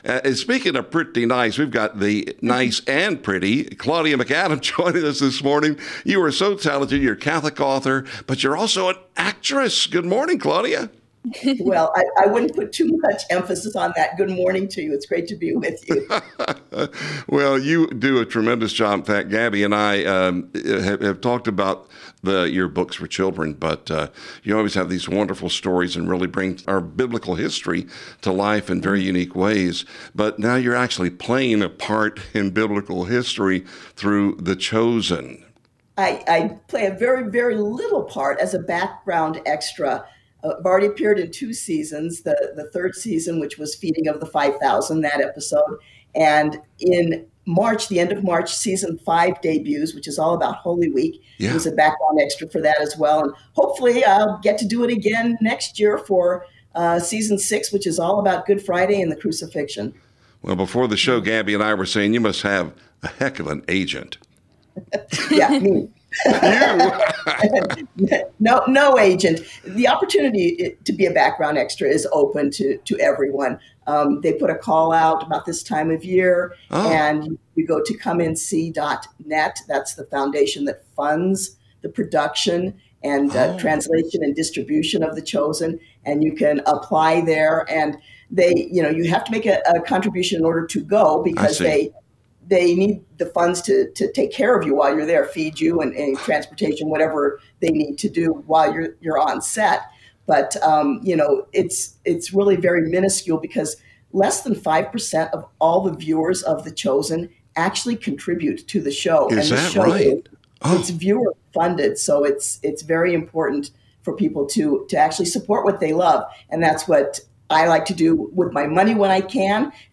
a fun discussion about